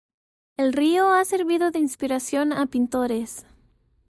Pronounced as (IPA) /seɾˈbido/